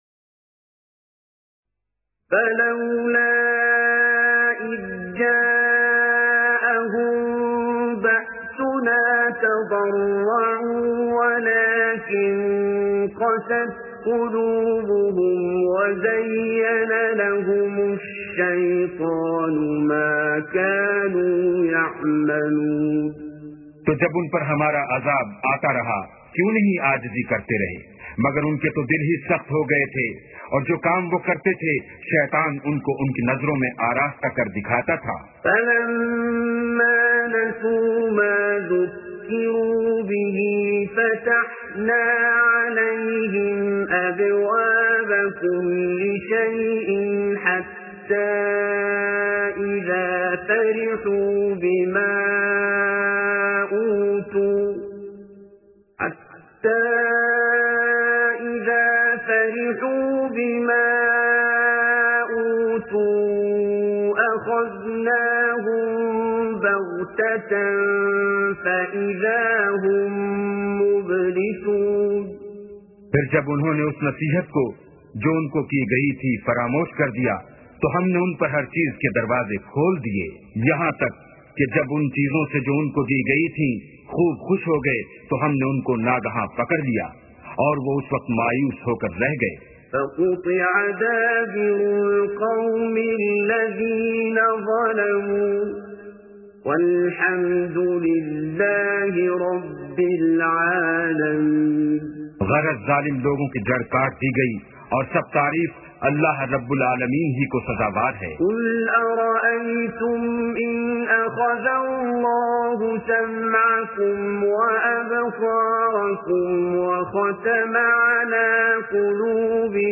Quran Recitation with Urdu Translation by Abdul Basit Abdul Samad, Audio MP3, Free Download,